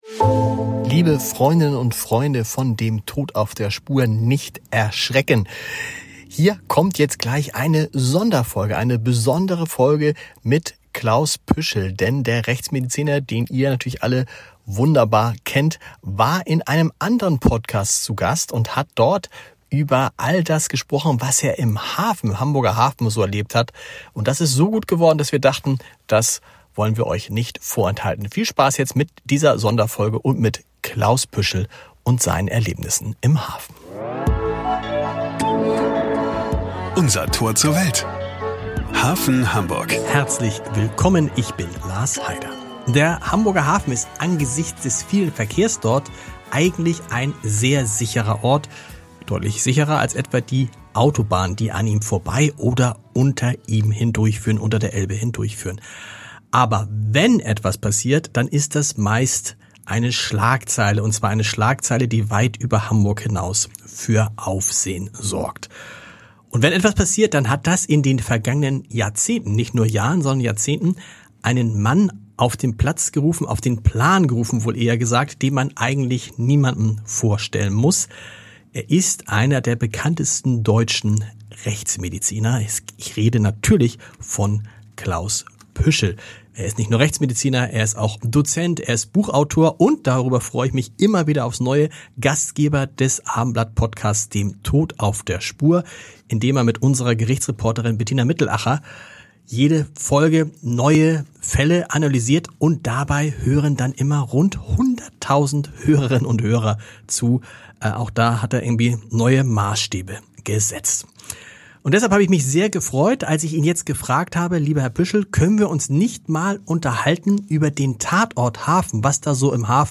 In unserer neuesten Podcast-Folge ist Klaus Püschel zu Gast und spricht über seine spektakulärsten Einsätze im Hafen.